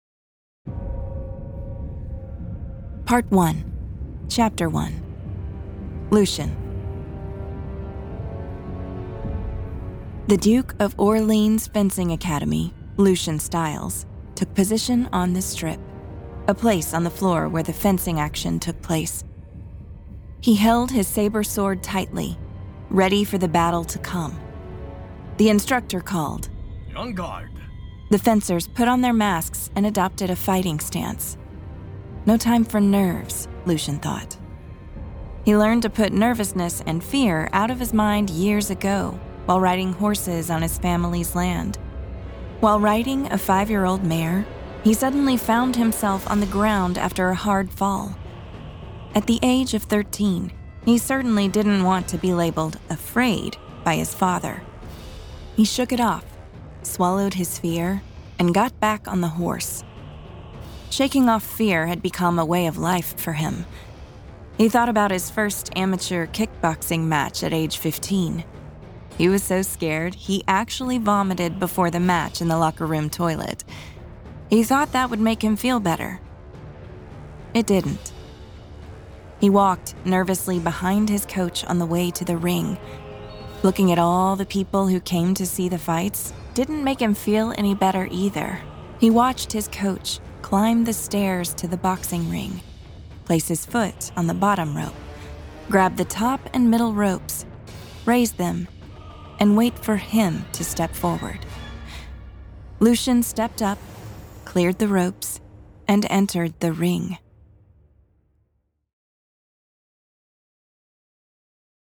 • Audiobook • 4 hrs, 59 mins
To best experience the Cinematic Music & Full Sound Design, please listen with earbuds or headphones!
Genre: Action / Adventure, Action / Adventure